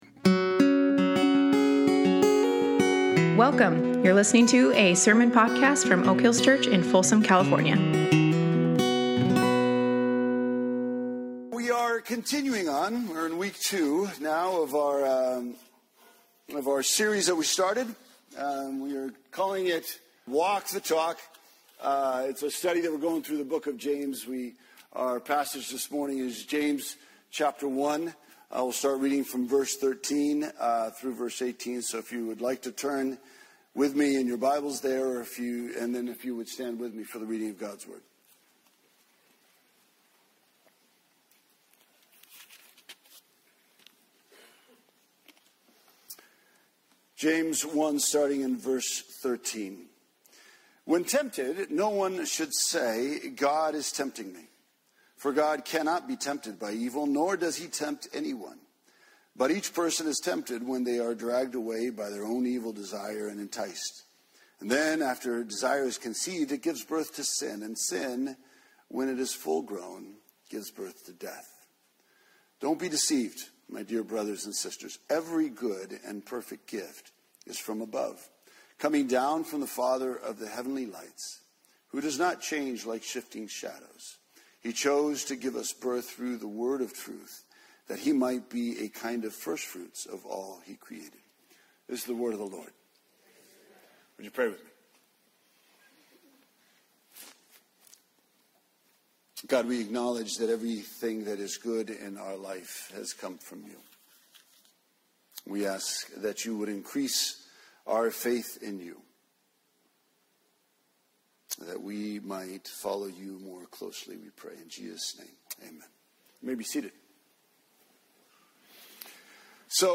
James 1:13-18 Service Type: Sunday Morning Life isn’t a Halmark card.